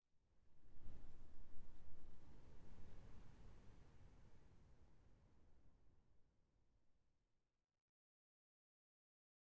minecraft-assets/assets/minecraft/sounds/block/sand/wind4.ogg at 8caafb37a52dccb6e782a697ae3d94f0fa761fbe
wind4.ogg